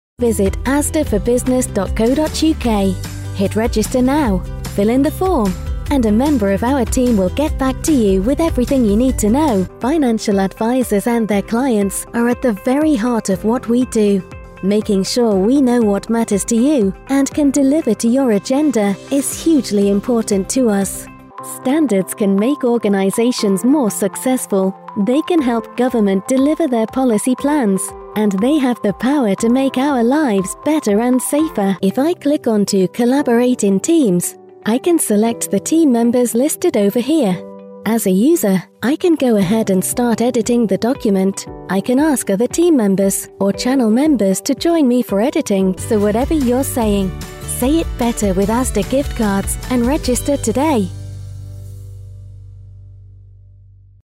britisch
Sprechprobe: eLearning (Muttersprache):
My voice can be described as authentic, sincere, assured and clear, an excellent choice for Radio & TV Commercials, Corporate and Explainer Videos, E-Learning & Training Narration, Telephone IVR and On-Hold Voiceovers, I have numerous character voices available for Commercials, Animation & Gaming too.